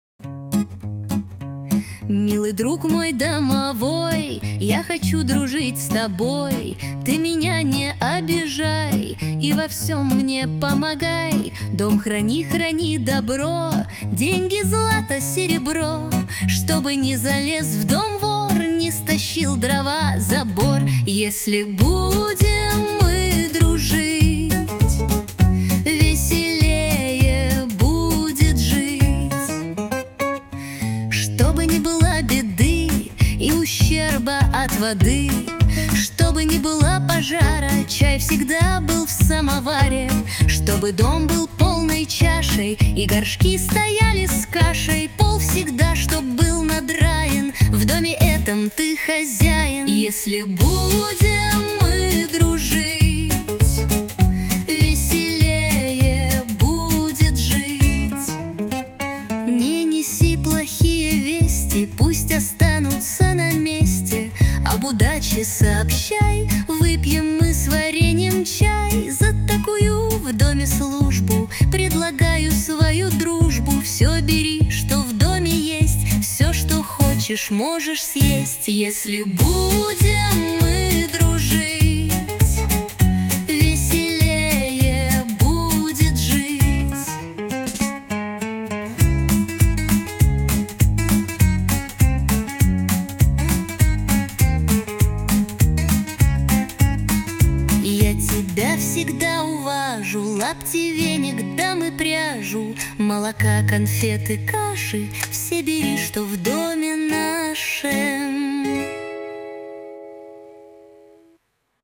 • Аранжировка: Ai
• Жанр: Фолк